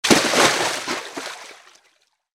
impact_water.mp3